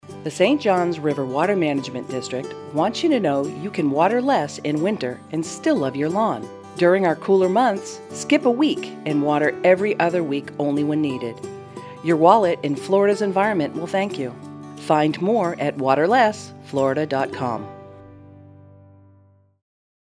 Public service announcements
Skip a Week--PSA 3 20sec.mp3